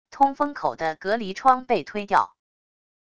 通风口的隔离窗被推掉wav音频